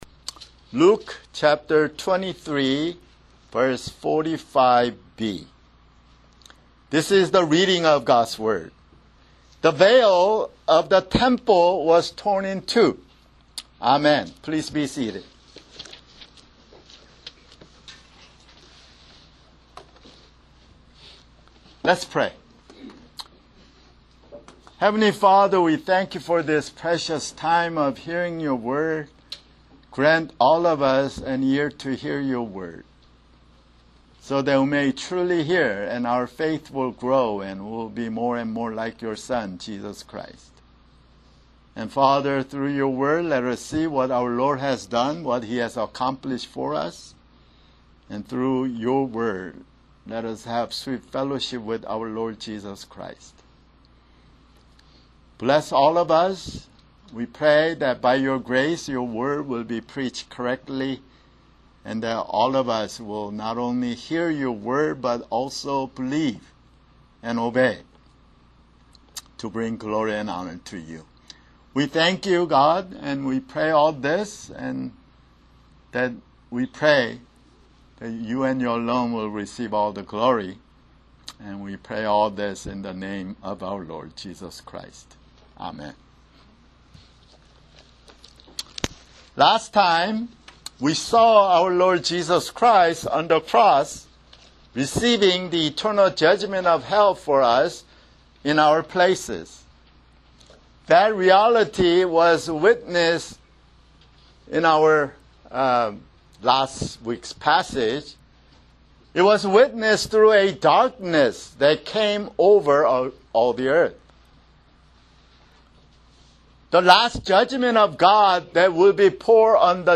[Sermon] Luke (170)